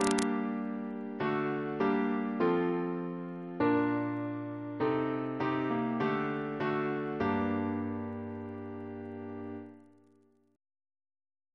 Single chant in G minor Composer: William Hine (1687-1730) Reference psalters: OCB: 47; PP/SNCB: 128; RSCM: 184